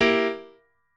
piano8_24.ogg